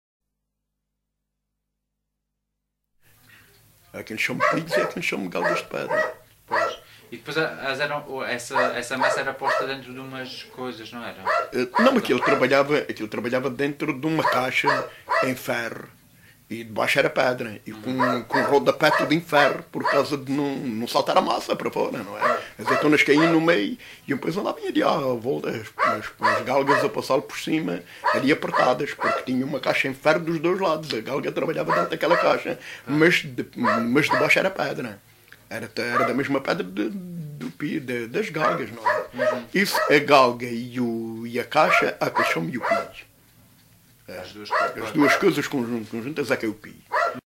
LocalidadeSapeira (Castelo de Vide, Portalegre)